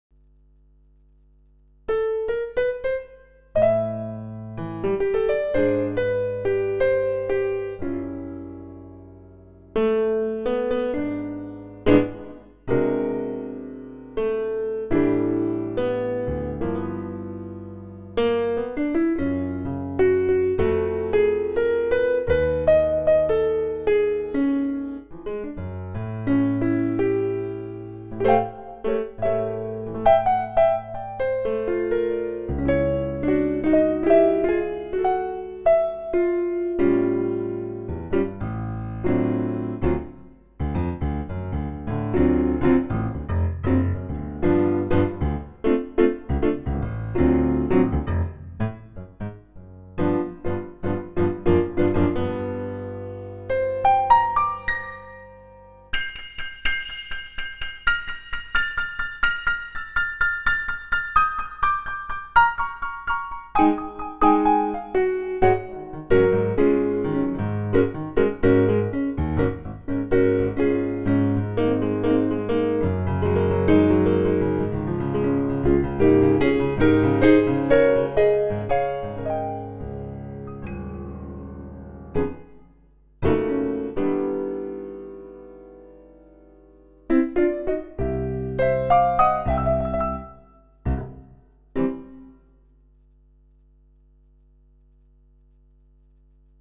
In fact I've just tried it with added Lexicon reverb through (4 fold) multi-expansion (another standard trick) and that works, too, in the studio type setting, anyhow (example :